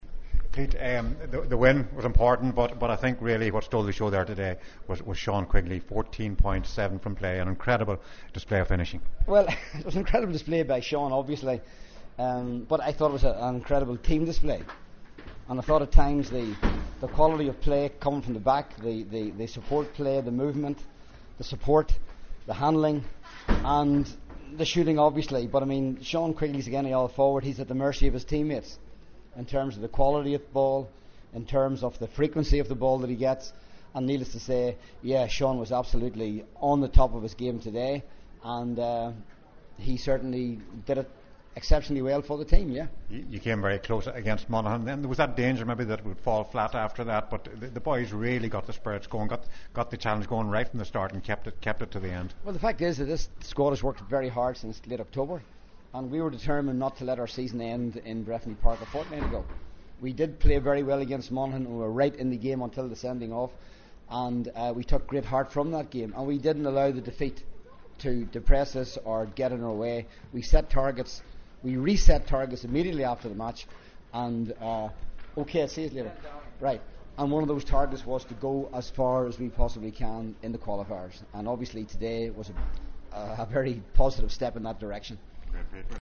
Pete McGrath after the match ….